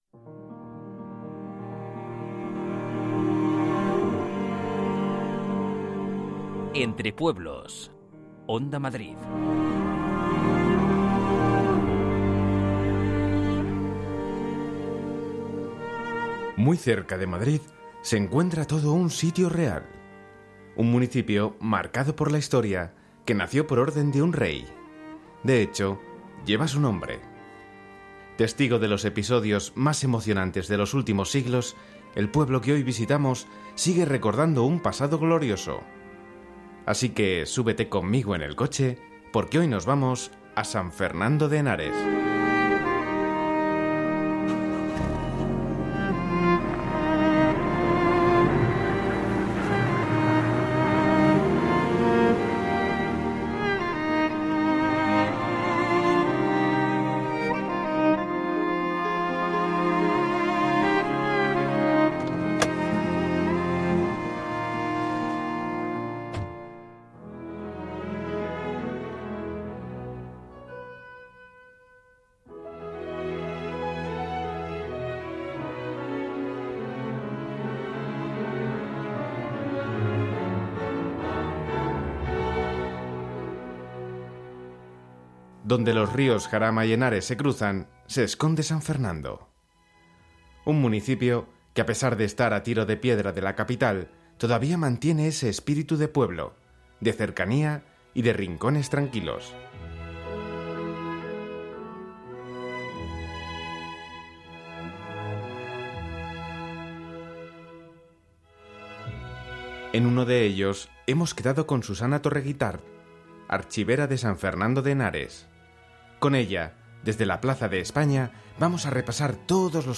Un recorrido emocional en el que los protagonistas compartirán sus recuerdos y anécdotas desde sus lugares más especiales. Entre Pueblos mezcla pasado y presente, entrevistas, relatos, música...